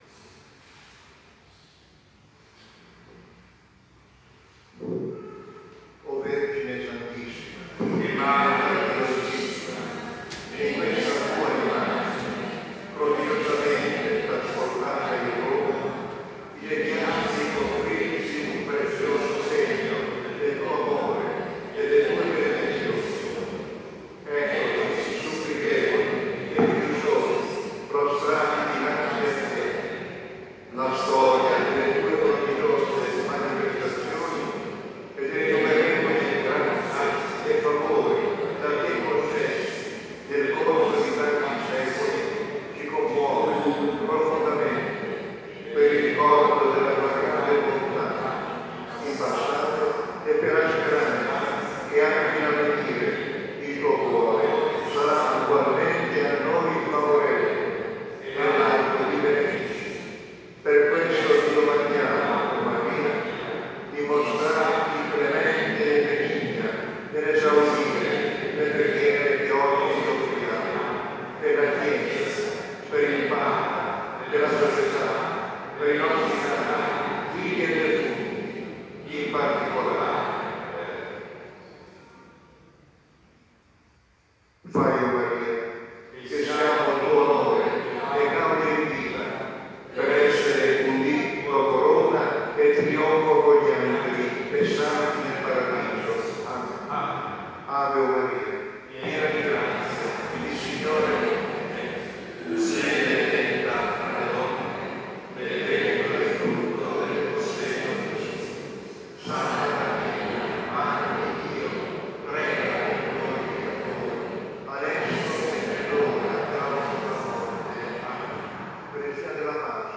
La preghiera alla Madonna di San Luca recitata da Sua Eminenza Card. Angelo Comastri con i fedeli
Il 27 giugno è stata la festa dell'Icona.